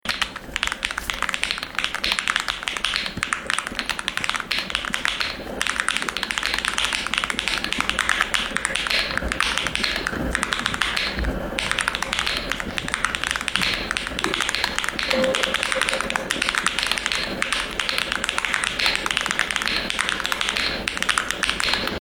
• Sound: Magnetic switches are generally quieter than clicky or tactile mechanical options, as the primary sound comes from the keycap bottoming out rather than internal switch mechanisms. Take the Akko Astrolink Magnetic Switch for example—its electronic pulse sound delivers a futuristic, high-tech feel.
Akko Astrolink Magnetic Switch
Whats-the-difference-between-mechanical-keyboard-Akko-Astrolink-Magnetic-Switch-MonsGeek.mp3